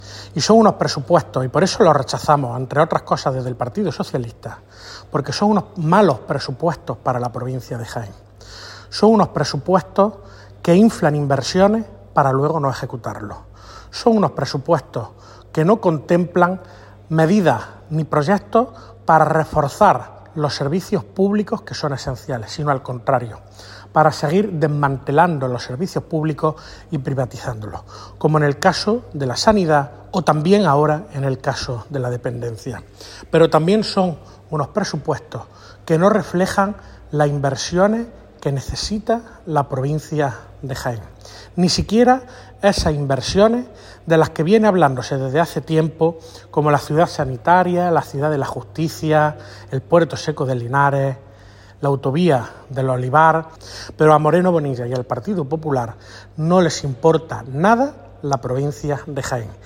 Cortes de sonido
Victor-Torres-confirmados-pesimos-presupuestos-Junta.mp3